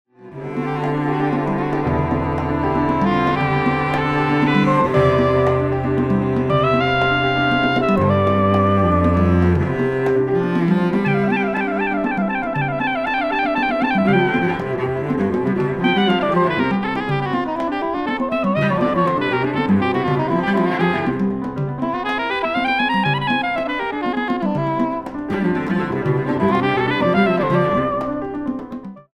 Soprano Sax, Cello, Piano Percussion